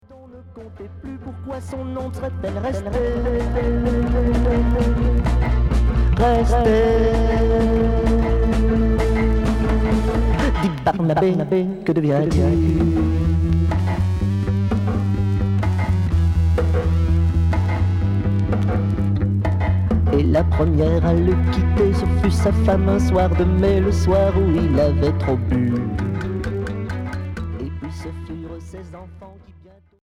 Psychédélique Unique 45t